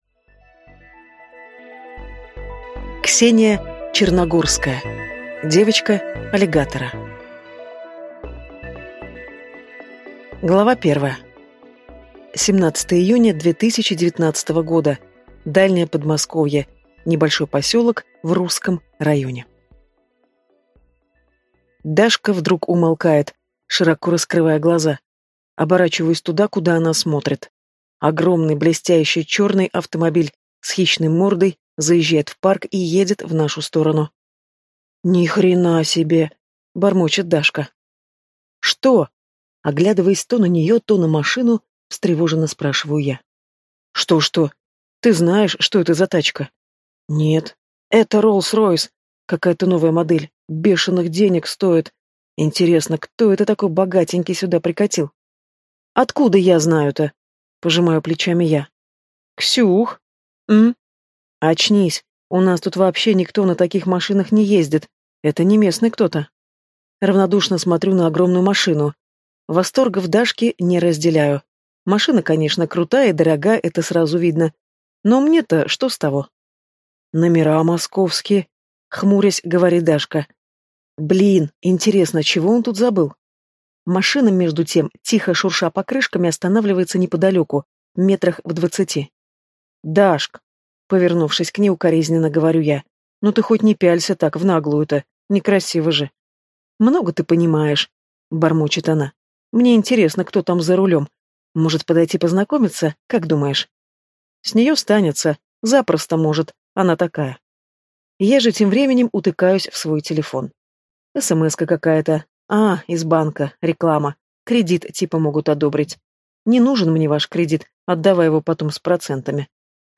Аудиокнига Девочка Аллигатора | Библиотека аудиокниг